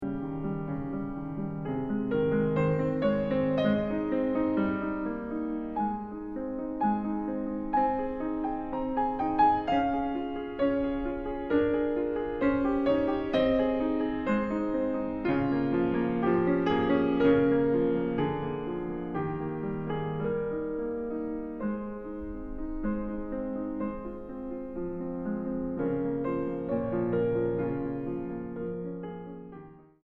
Largo 1:48